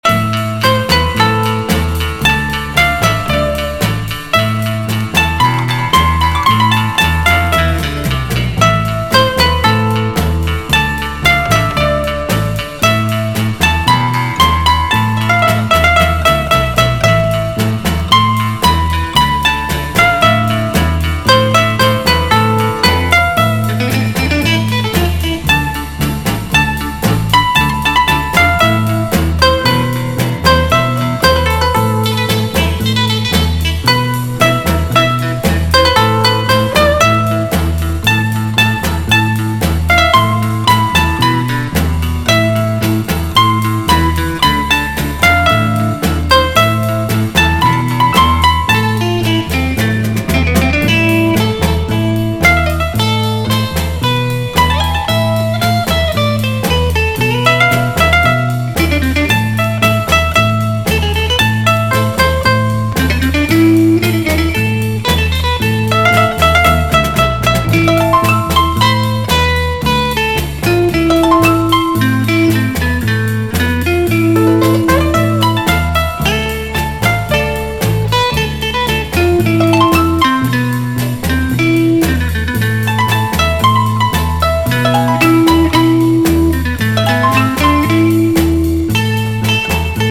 陽気に踊れるサーフ・ストンパー / ロックンロール！